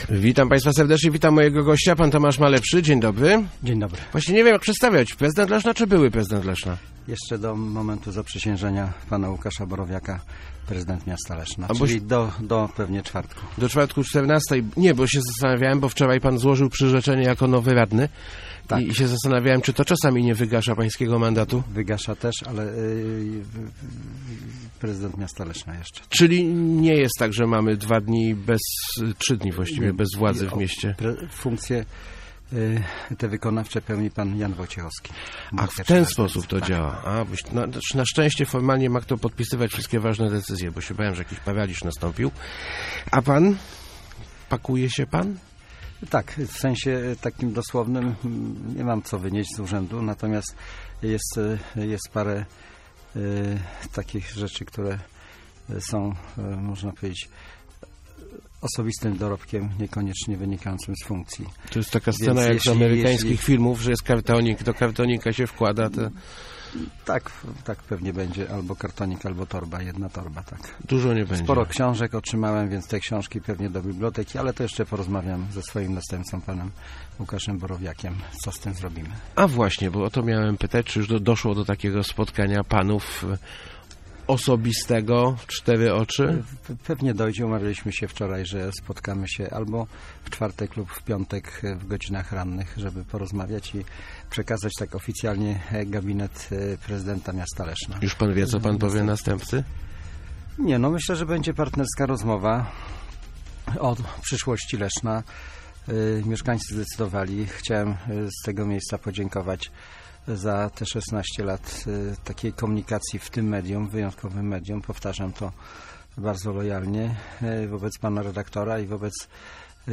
Mam prawo do emerytury i z niego skorzystam - mówił w Rozmowach Elki ustępujący prezydent Leszna Tomasz Malepszy. Zapewnił o swojej gotowości do współpracy z Łukaszem Borowiakiem, choć przyznał też, że jako radny będzie najprawdopodobniej w opozycji.